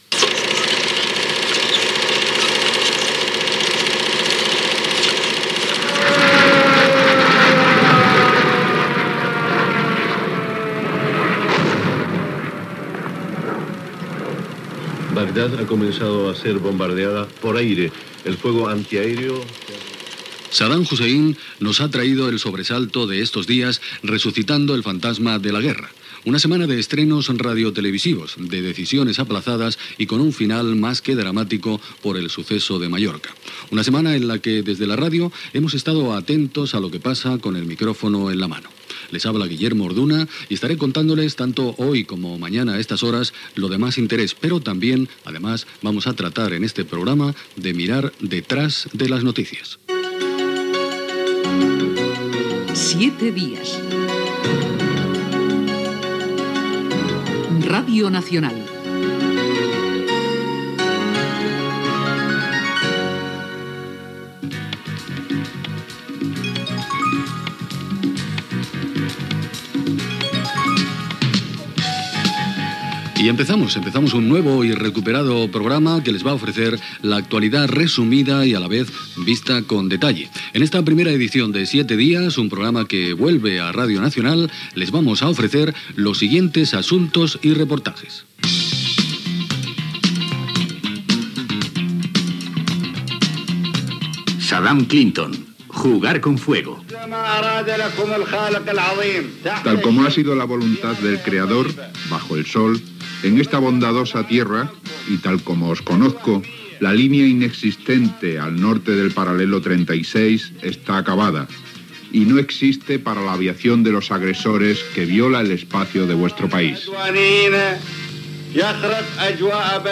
Presentació, careta del programa, sumari de continguts (bombardejos a Iraq, pagar pels medicaments receptats, nens i televisió, etc.), "Mosaico" el resum dels 7 dies en 7 minuts.
Informatiu